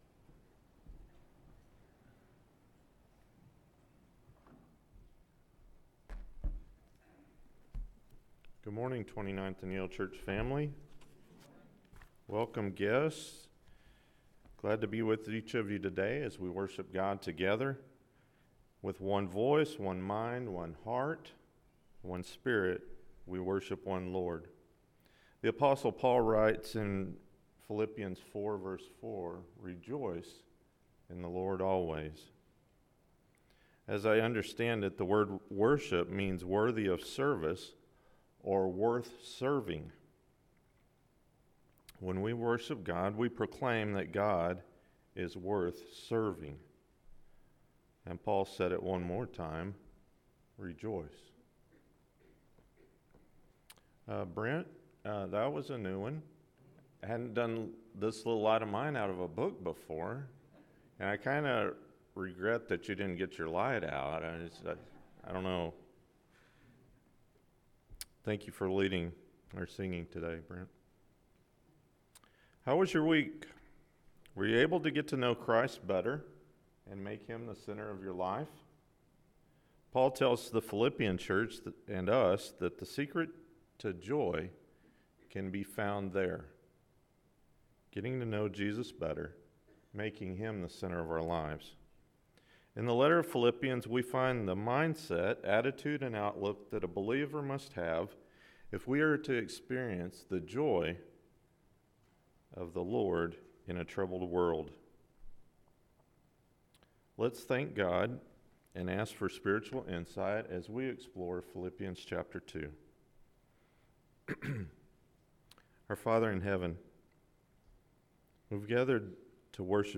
Shining Like Stars – Philippians 2 – Sermon — Midtown Church of Christ